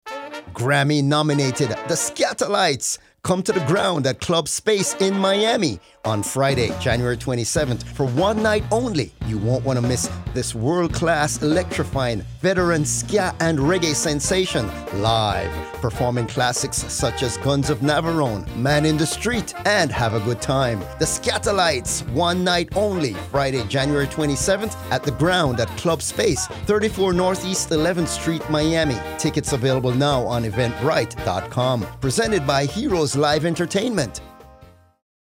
WDNA Radio Spot: